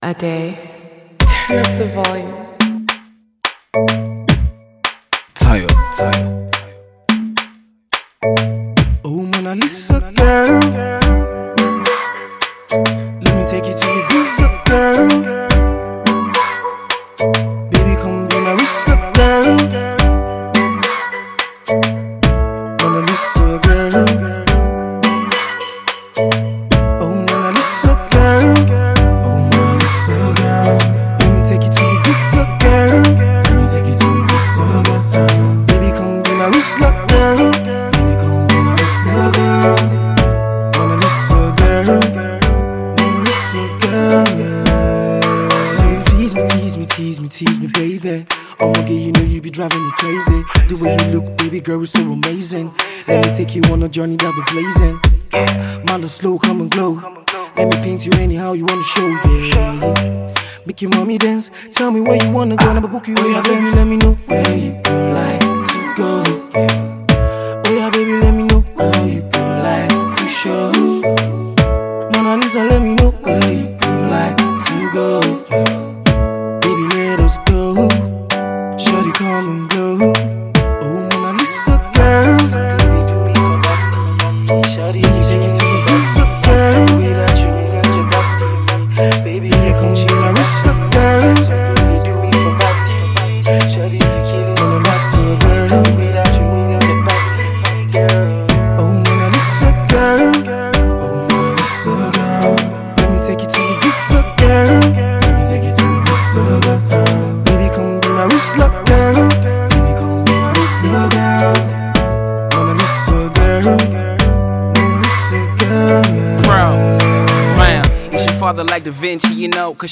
Electrifying afro beat song